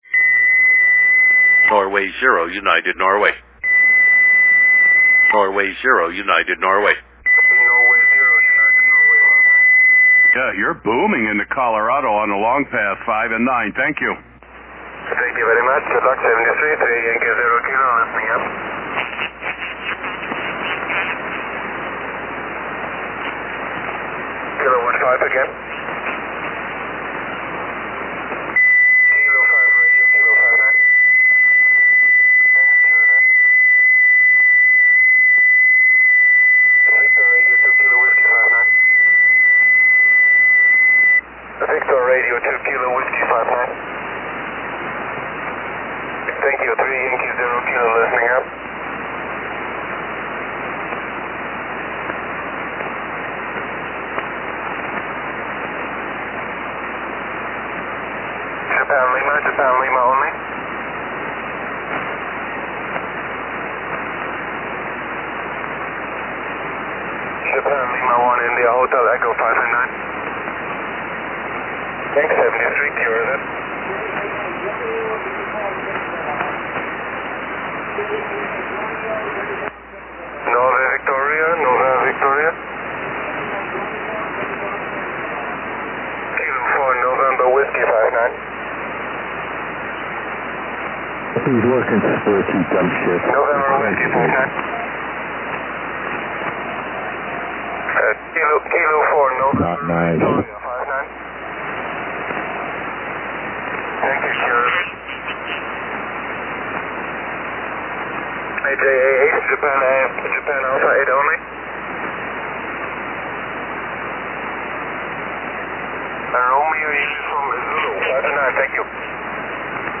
What a signal from 3Y0K on Bouvet via the Longpath this morning! After this SSB recording he only got stronger (figures some idiot with his FT8 was QRMing 3Y0K).